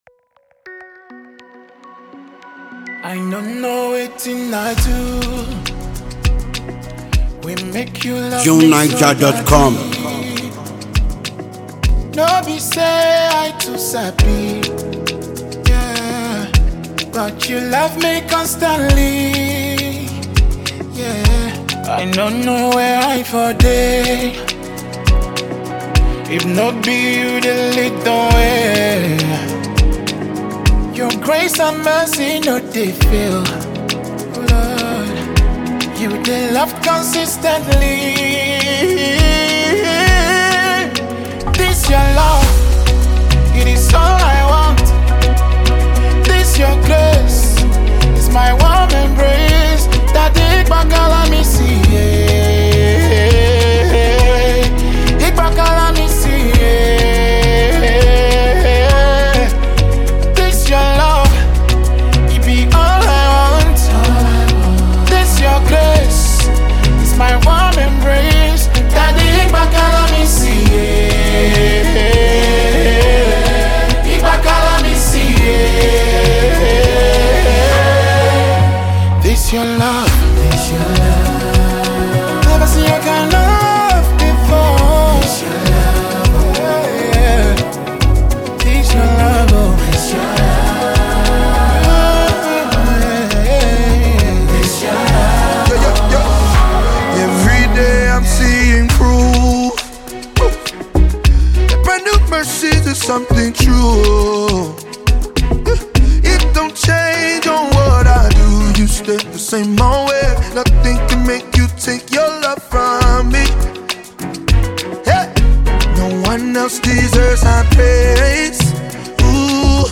a well-known Nigerian gospel music artist and songwriter
heartfelt new song